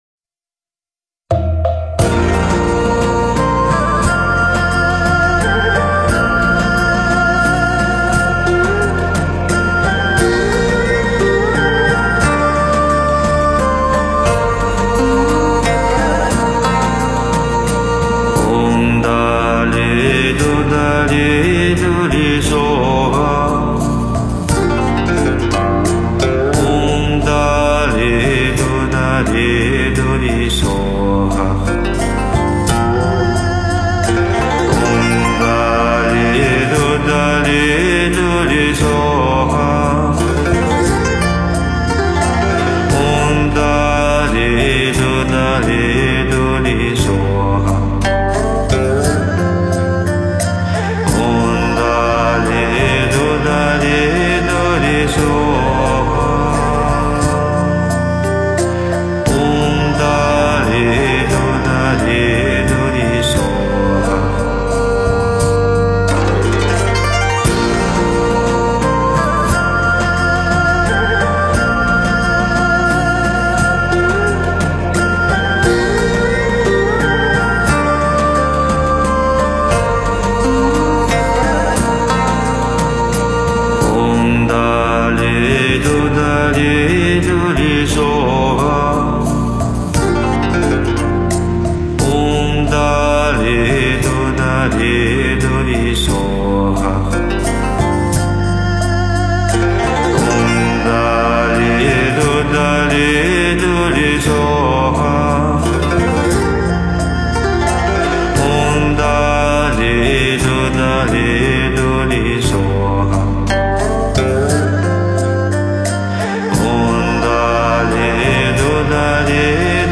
佛音 诵经 佛教音乐 返回列表 上一篇： 大悲咒(梵音